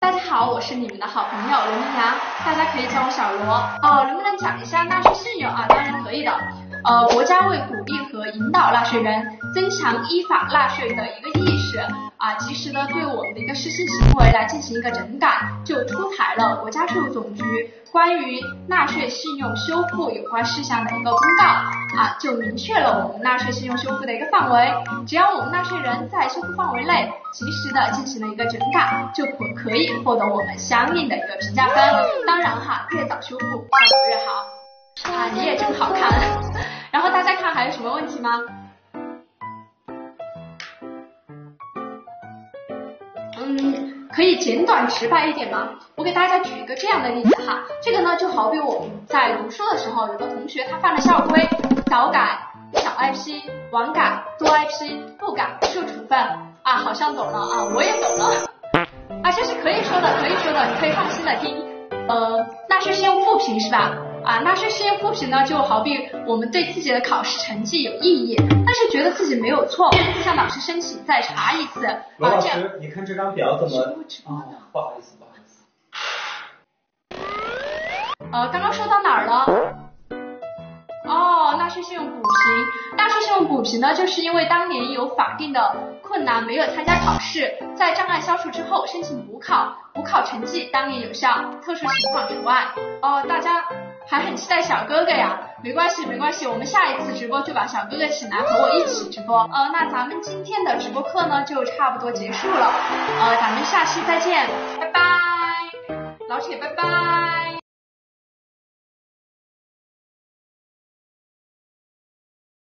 直播读弹幕：纳税信用修复、复评、补评是啥？小姐姐这样翻译